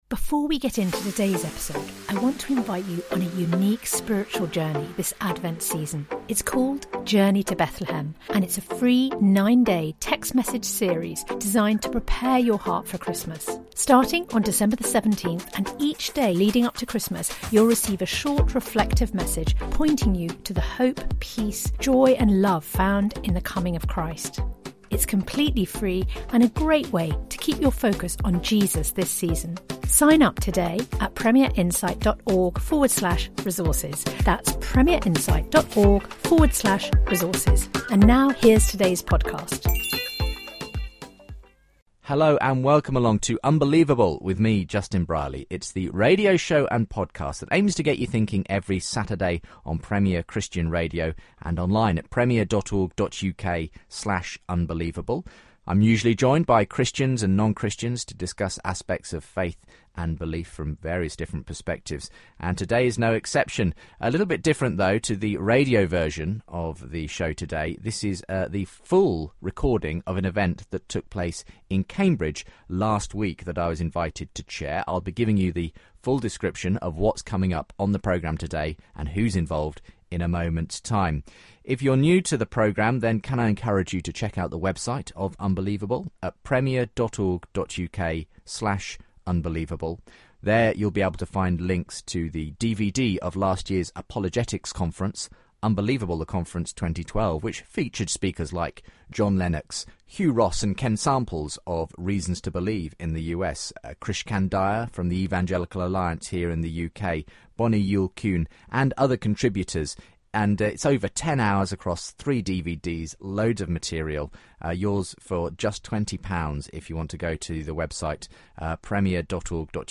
a discussion with the two thinkers in front of an audience of Cambridge students who ask their own questions too